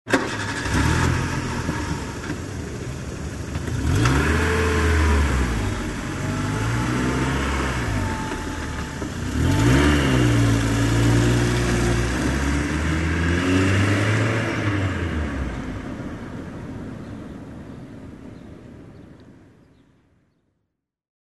Звуки заводящейся машины
Звук машины: завелась и поехала